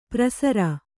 ♪ prasara